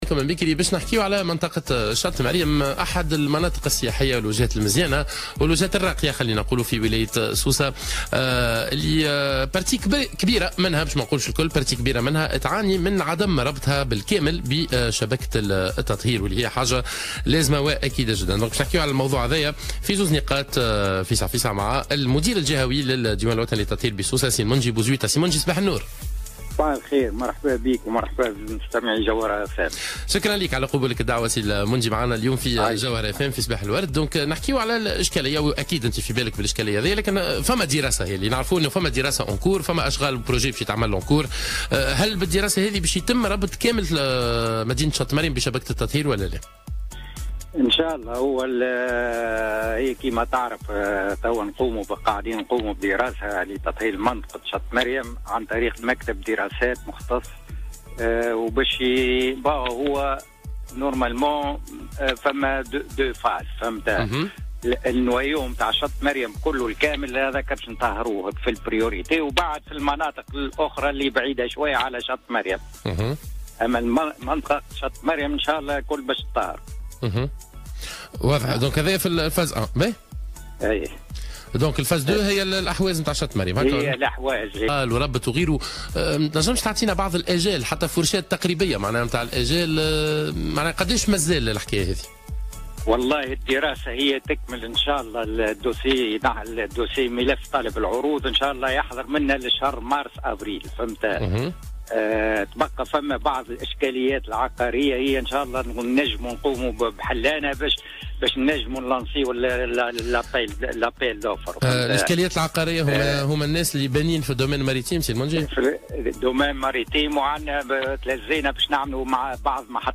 مداخلة هاتفية في برنامج "صباح الورد" على موجات الجوهرة اف ام